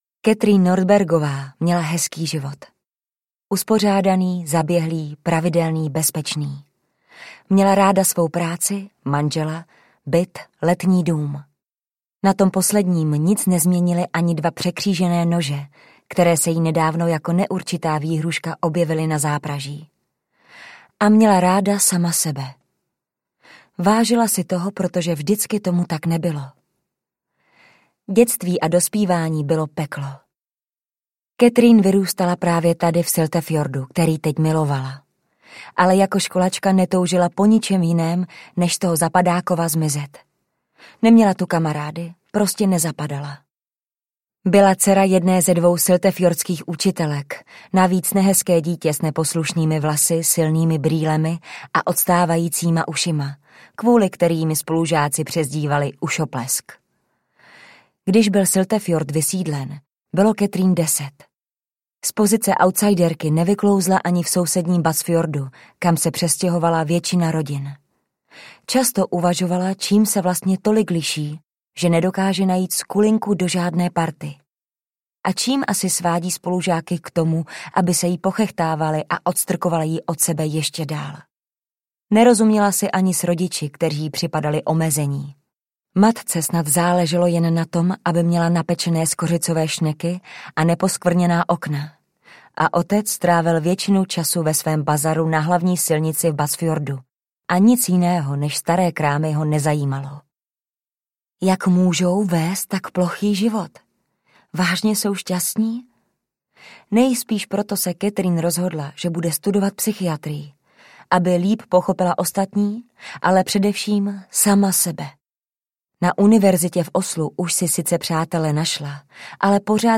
Zmizela v mlze audiokniha
Ukázka z knihy